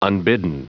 Prononciation du mot unbidden en anglais (fichier audio)
Prononciation du mot : unbidden